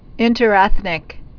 (ĭntər-ĕthnĭk)